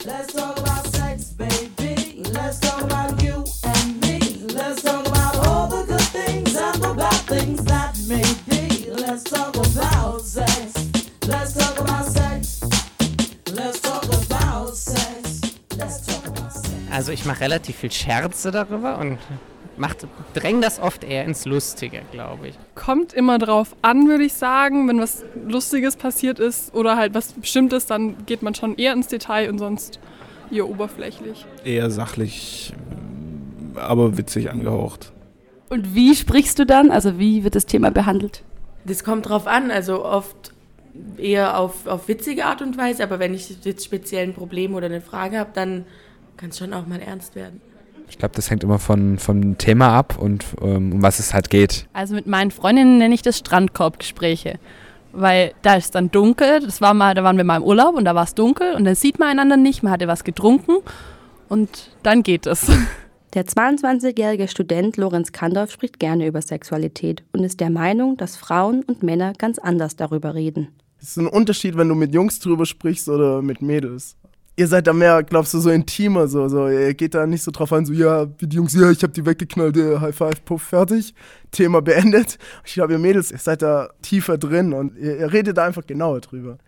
Sendung "Tabus": Teaser